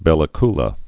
(bĕlə klə)